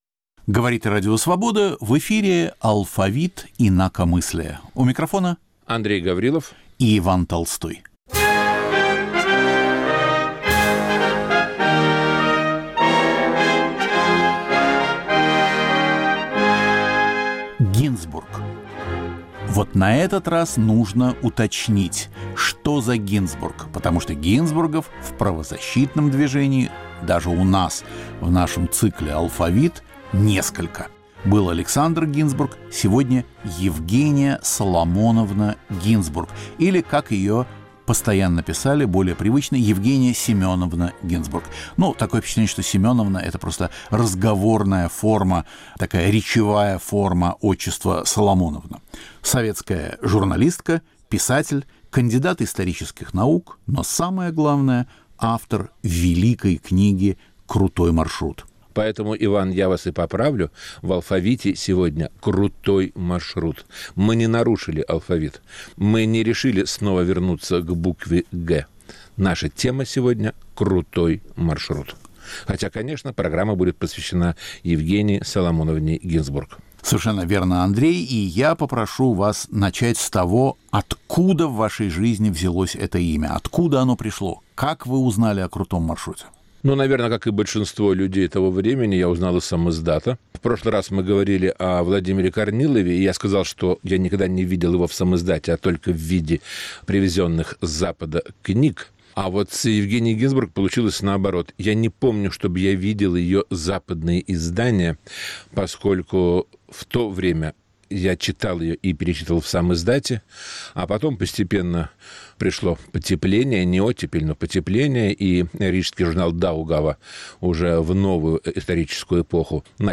Разговор об одной из сильнейших книг, посвященных сталинским репрессиям, тюремному марафону и ГУЛагу. Первый том книги вышел в 1967 году и принес Евгении Гинзбург (матери Василия Аксенова) всемирную славу.